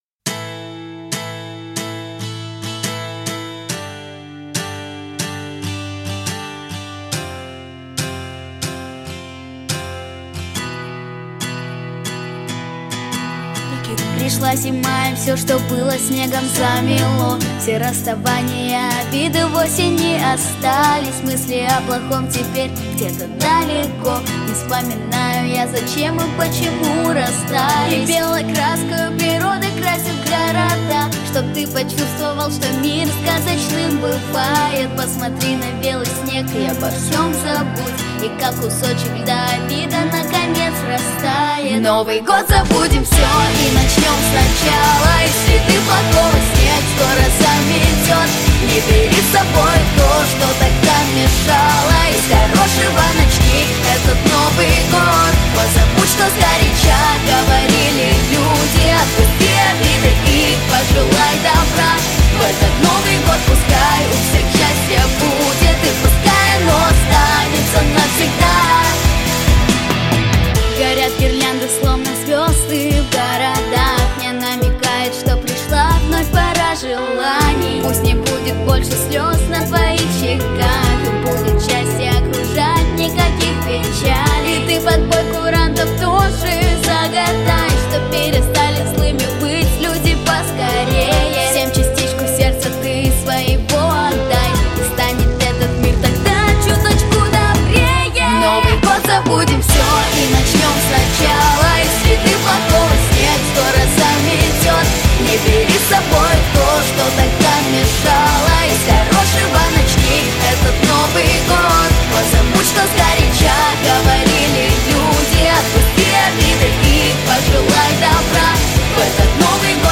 🎶 Детские песни / Песни на праздник / Песни на Новый год 🎄